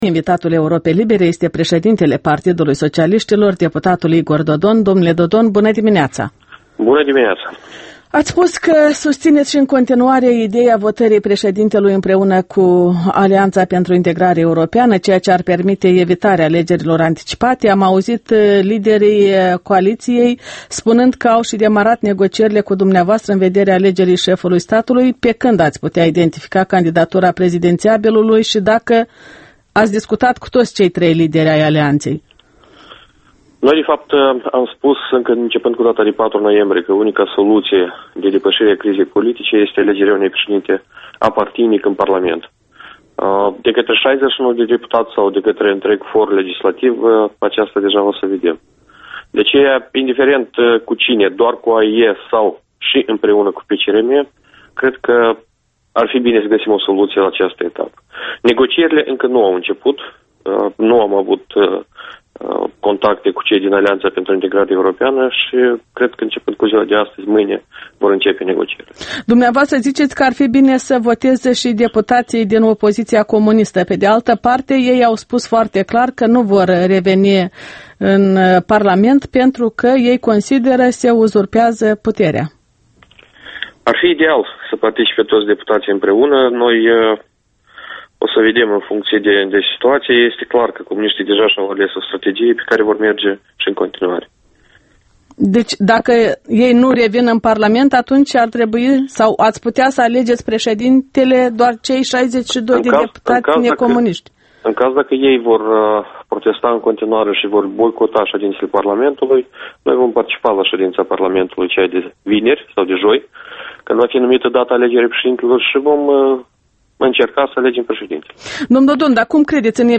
Interviul dimineții la Europa Liberă: cu Igor Dodon despre perspectivele politice moldovenești